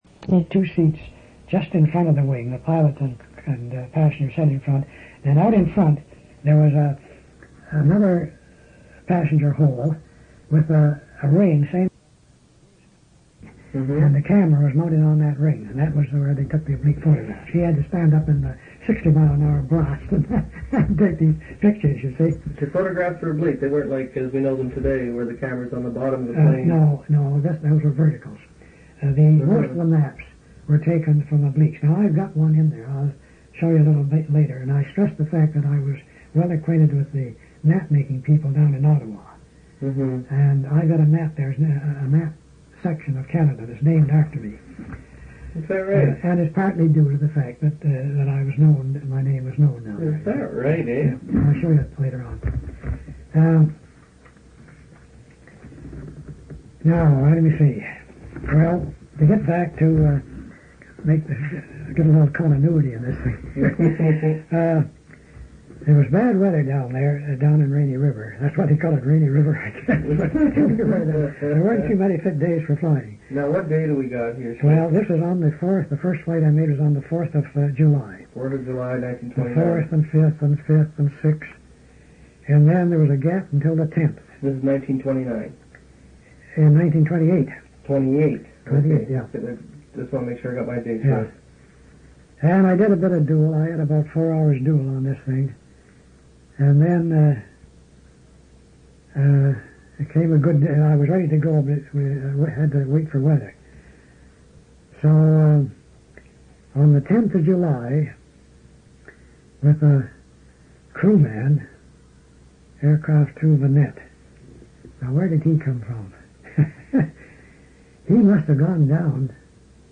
Interview took place on June 21, 26, July 3, 6, 19 and August 23, 1979.